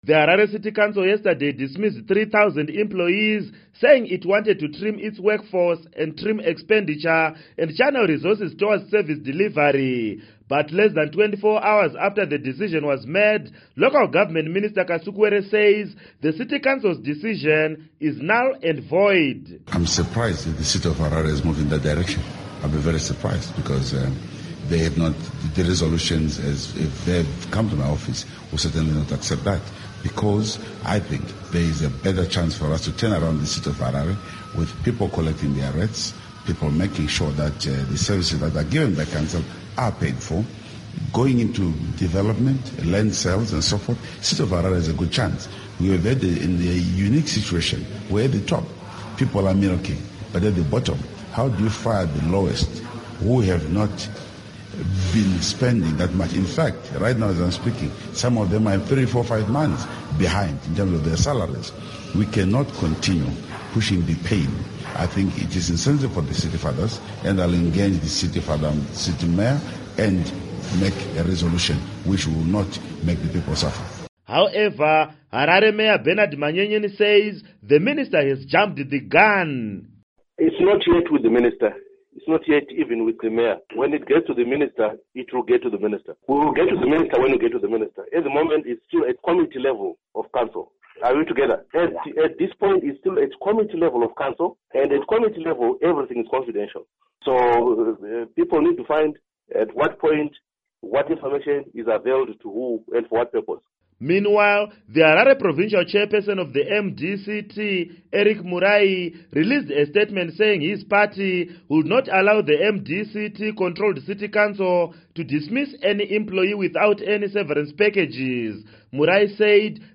Report on Firing of Harare Council Workers